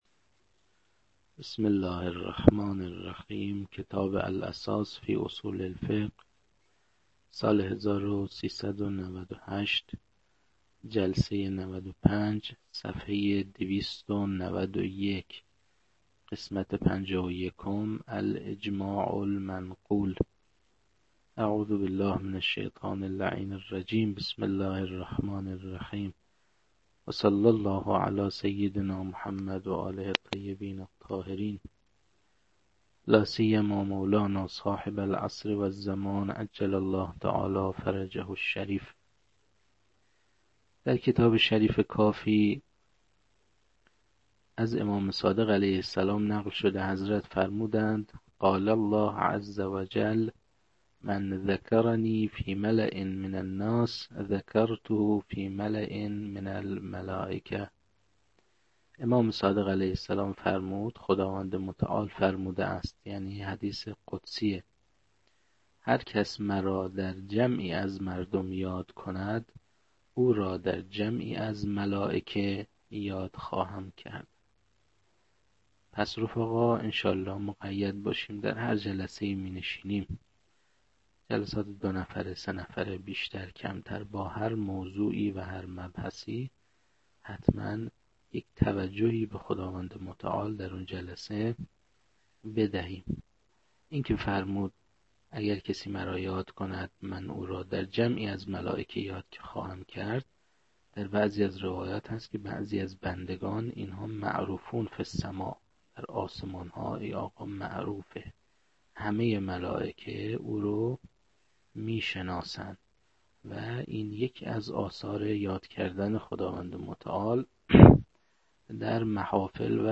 در این بخش، کتاب «الاساس» که اولین کتاب در مرحلۀ آشنایی با علم اصول فقه است، به صورت ترتیب مباحث کتاب، تدریس می‌شود.
در تدریس این کتاب- با توجه به سطح آشنایی کتاب- سعی شده است، مطالب به صورت روان و در حد آشنایی ارائه شود.